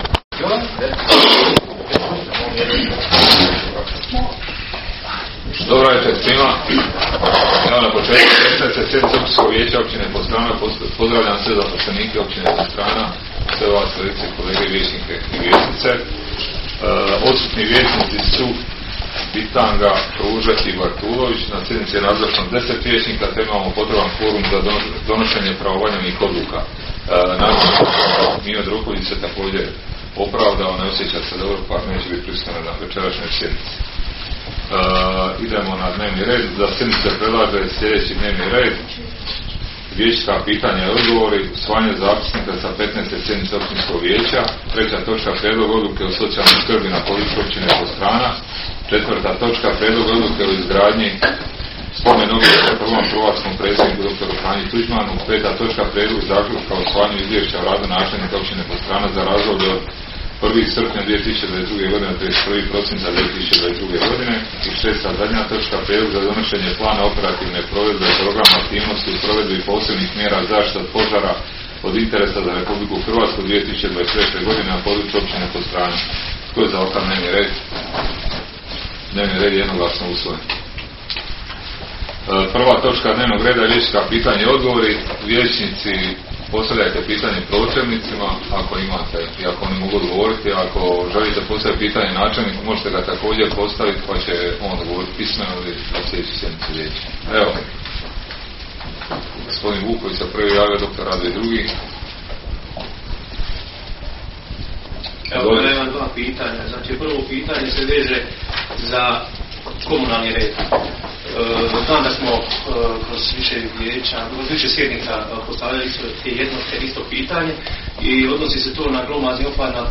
Sjednica će se održati dana 23. ožujka (četvrtak) 2023. godine u 19,00 sati u vijećnici Općine Podstrana.